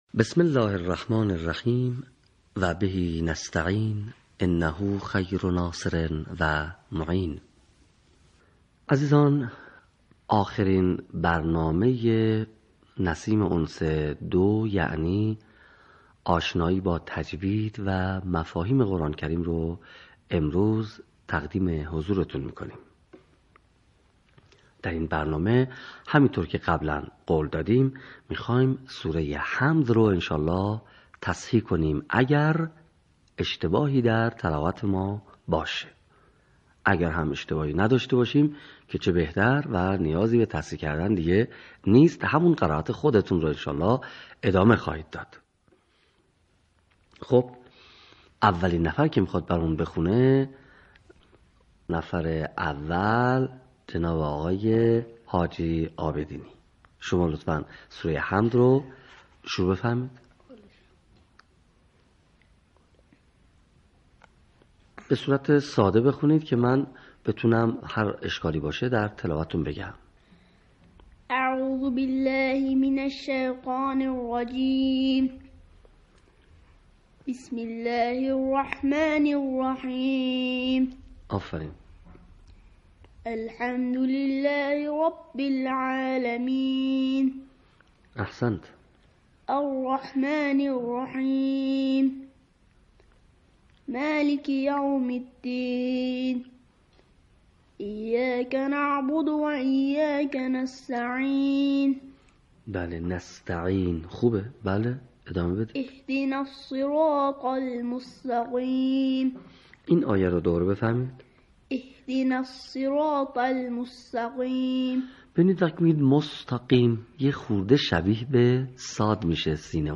صوت | آموزش قرائت سوره مبارکه حمد